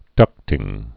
(dŭktĭng)